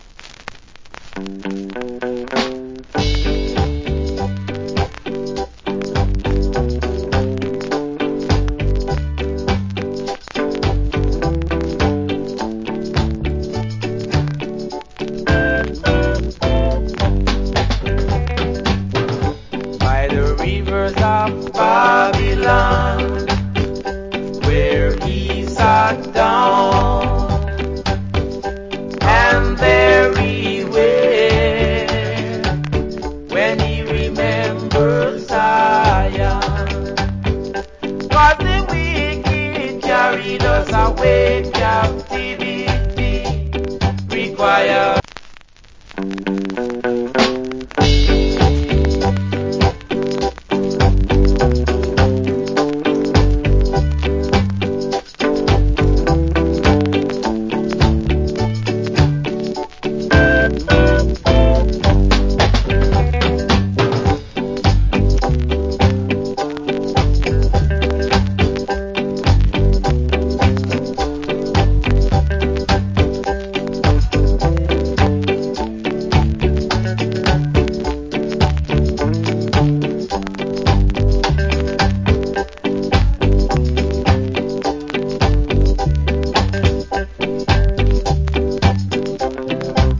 Wicked Reggae.